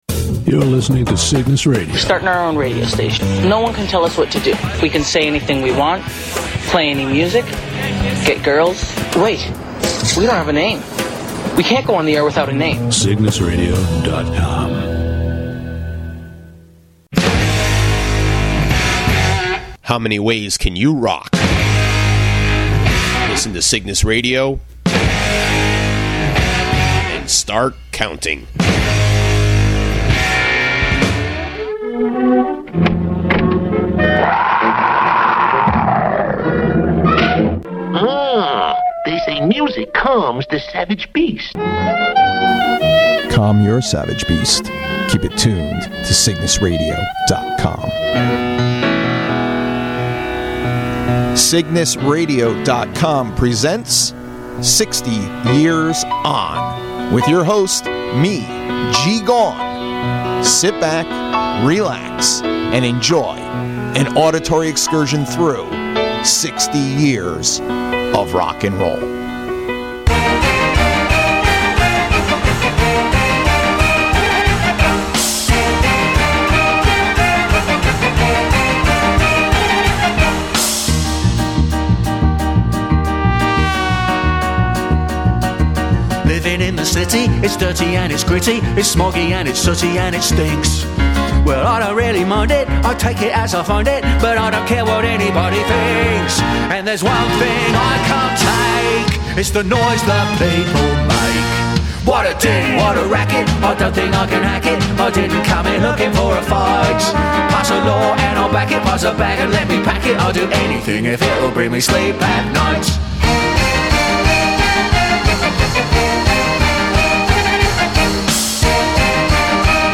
Genre: Rock & Roll.